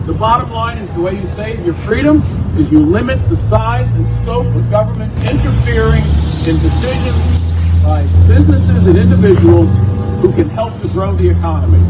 Maryland Congressman Andy Harris who leads the House Freedom Caucus was a featured speaker at the event.